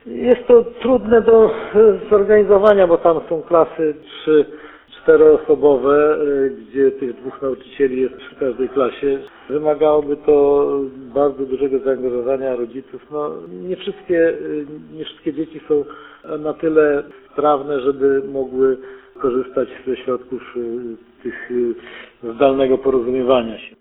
Mówi Radiu 5 starosta ełcki Marek Chojnowski.